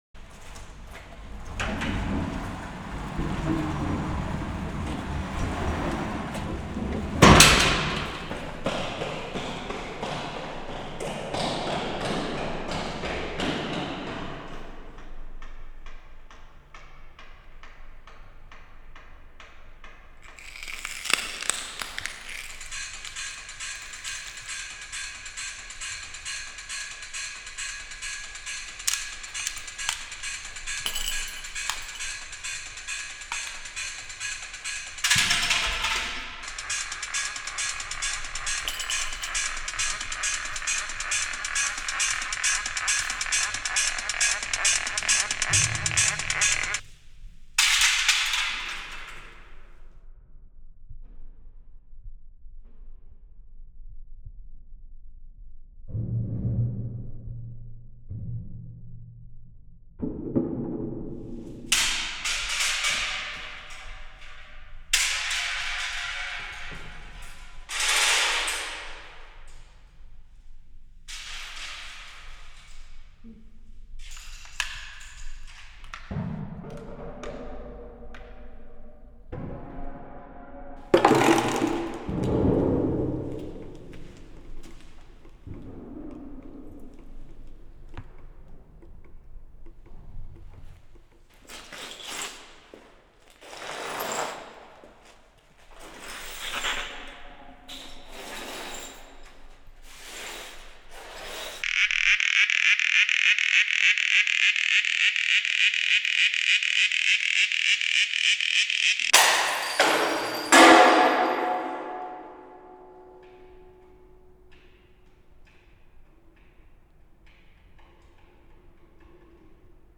Usine désaffectée, rue Mac Mahon, Nancy
performance